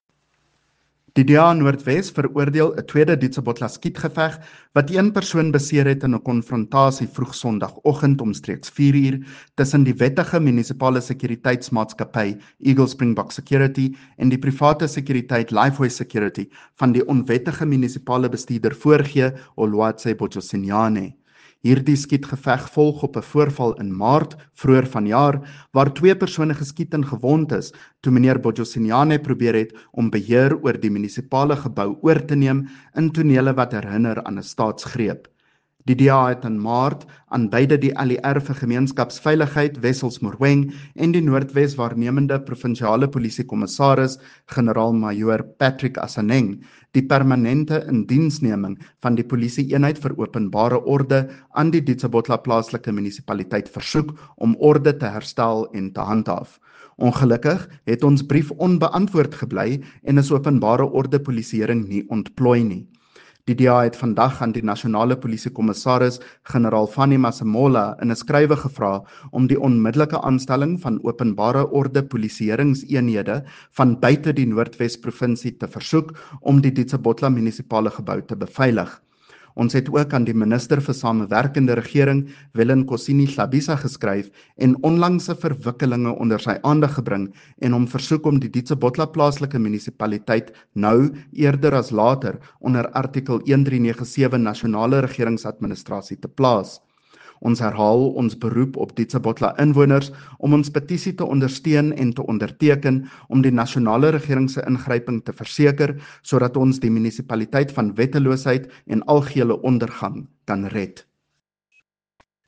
Note to Broadcasters: Please find linked soundbites in English and
Afrikaans by CJ Steyl MPL.